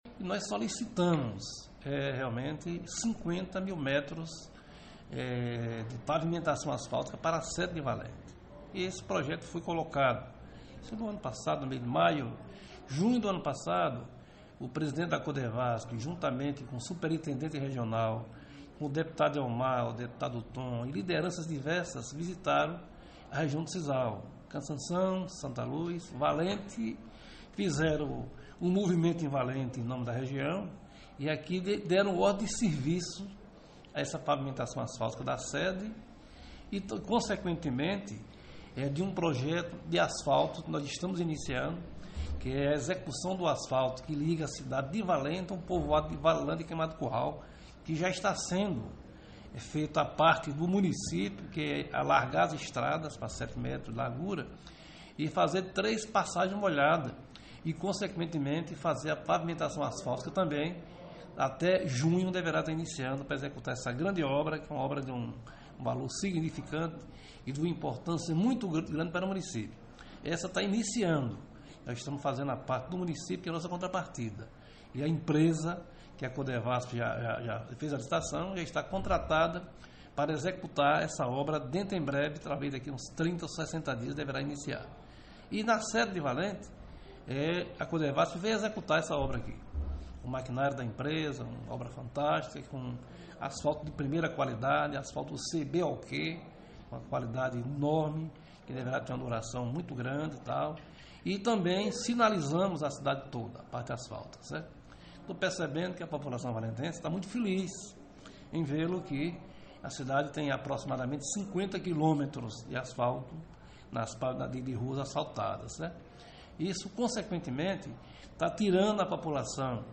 O prefeito contou ao CN como se desenvolveu o projeto, que agora já se tem os frutos e outros em breve com o asfalto que liga a sede aos Povoado Valilândia e Queimada do Curral – Ouça